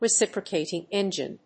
音節recíp・ro・cat・ing èngine 発音記号・読み方
/‐ṭɪŋ‐(米国英語)/